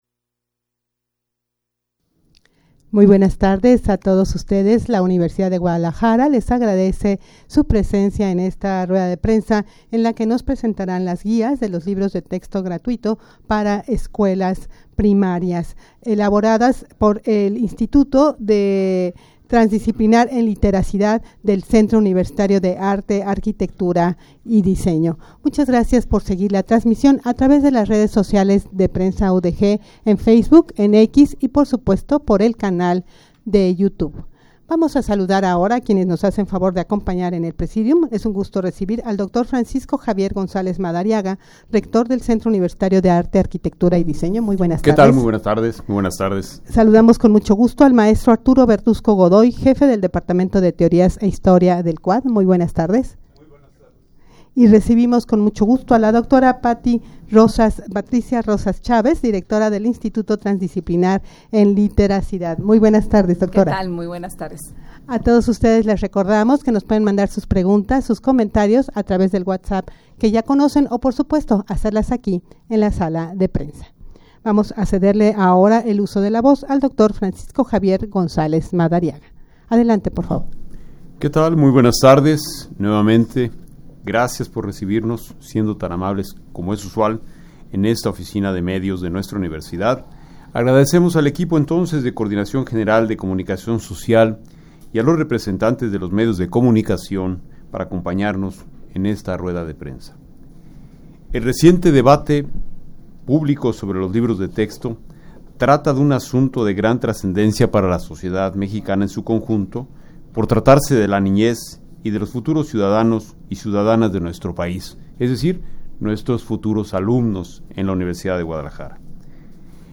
Audio de la Rueda de Prensa
rueda-de-prensa-presentacion-de-las-guias-de-los-libros-de-texto-gratuitos-para-escuelas-primarias-.mp3